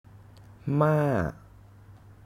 Tone: starts mid, drops quickly
ToneMidLowFallingHighRising
Phoneticmaamàamâamáamǎa